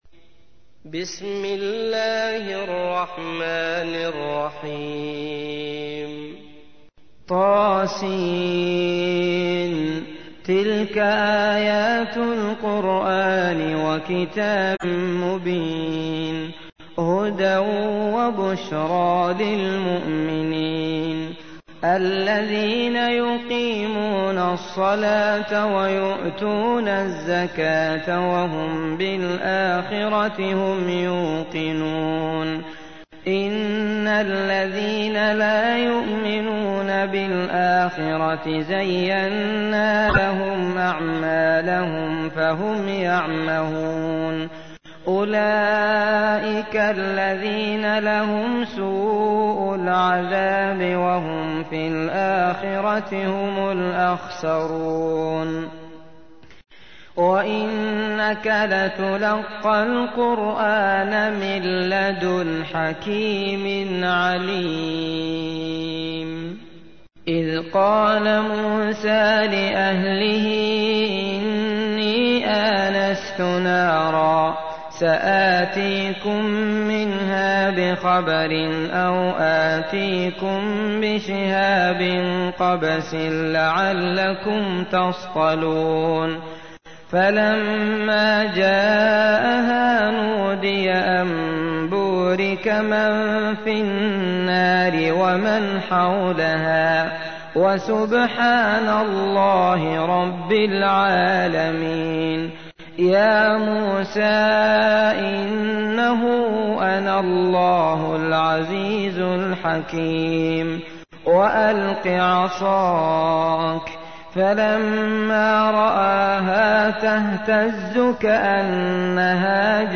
تحميل : 27. سورة النمل / القارئ عبد الله المطرود / القرآن الكريم / موقع يا حسين